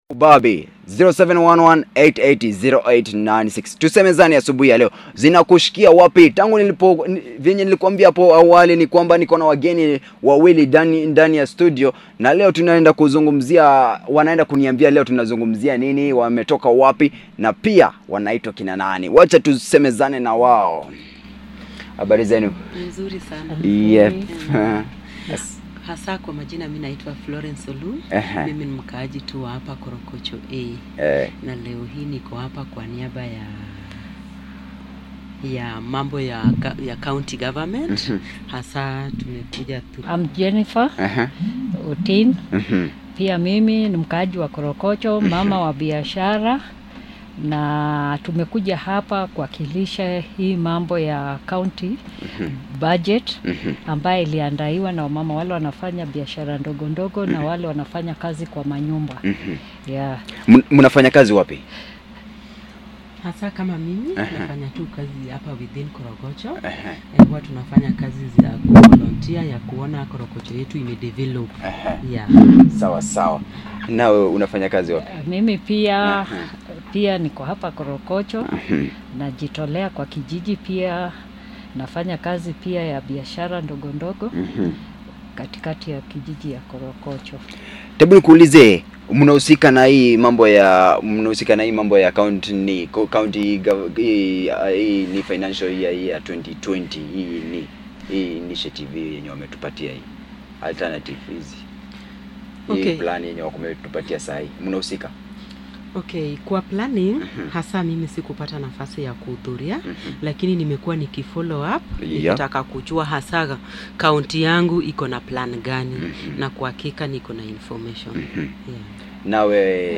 The talk show is produced by Koch Fm on the role of community members on public participation. The talk show is part of ‘our county our responsibility project’ which was undertaken to empower the local population to actively participate in all democratic processes at the county level.